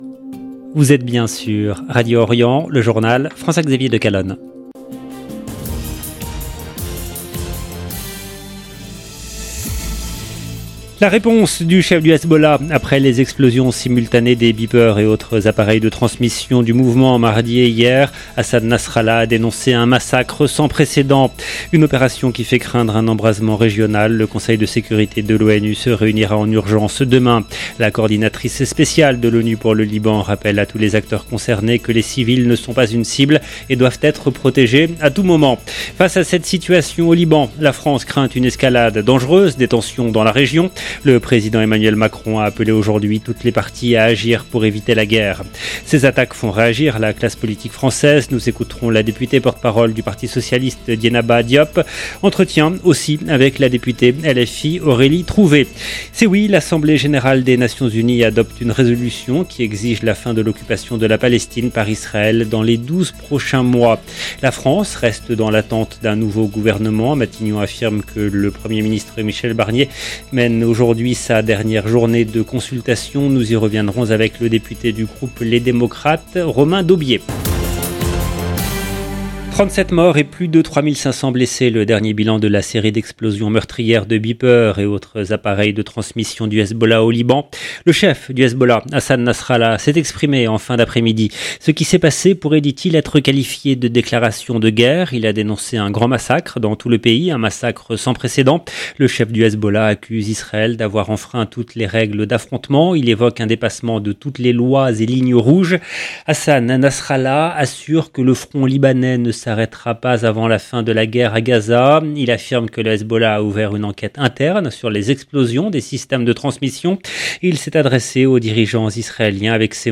EDITION DU JOURNAL DU SOIR EN LANGUE FRANCAISE DU 19/9/2024
Nous écouterons la députée, porte parole du Parti Socialiste Dieynaba Diop. Entretien également avec la député LFI Aurélie Trouvé.